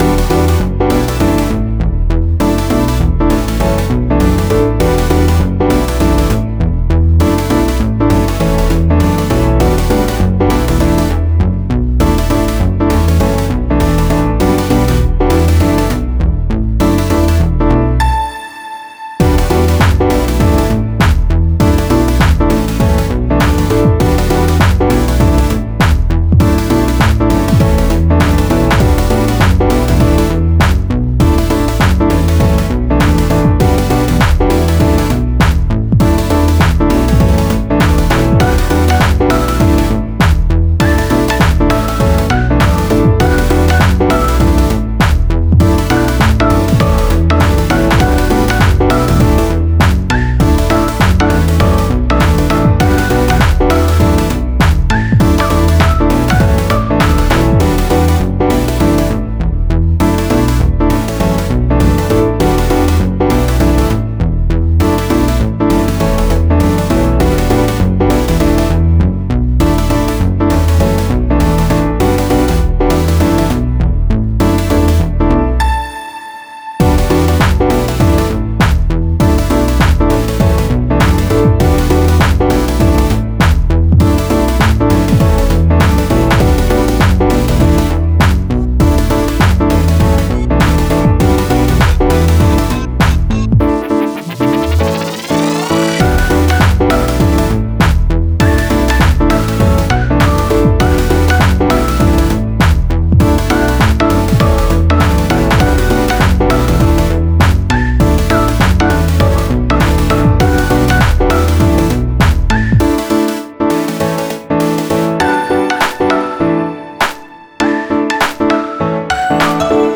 Soundtrack　 BPM:100　 UNSET
エモ カフェ 雑談